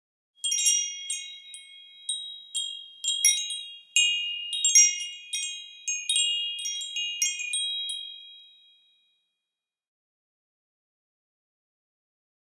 horror
Horror Wind Chimes Exterior